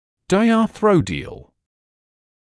[ˌdaɪɑː’θrɔdɪəl][ˌдайаː’сродиэл]диартродиальный